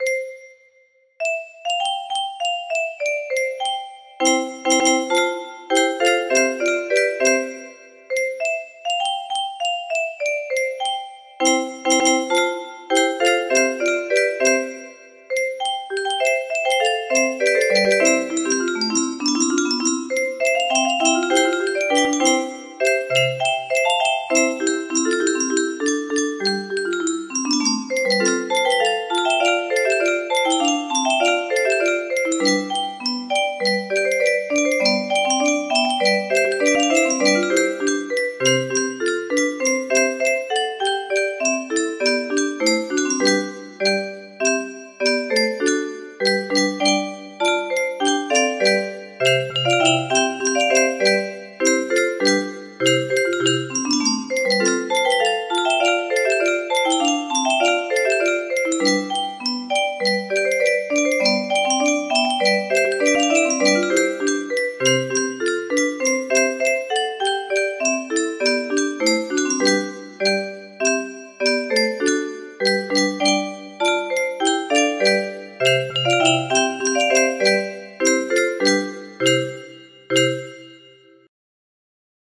Fair Phyllis - John Farmer music box melody